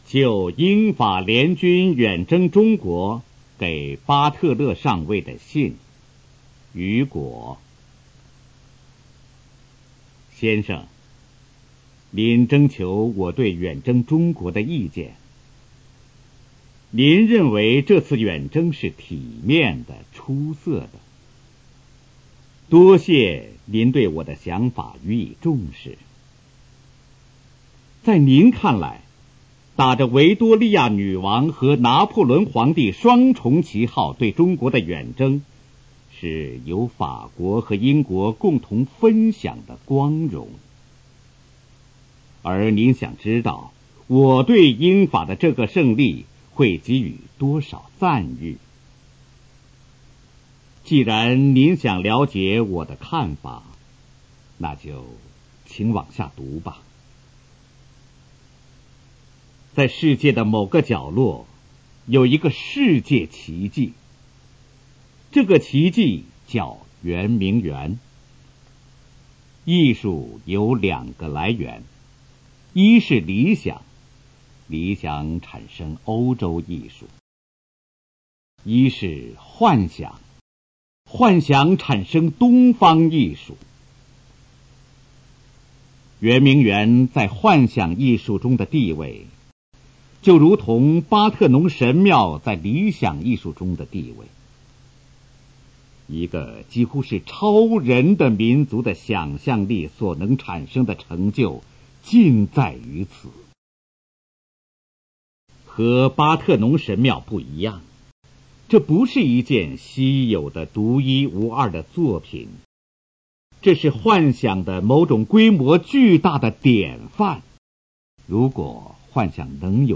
《就英法联军远征中国给巴特勒上尉的信》朗读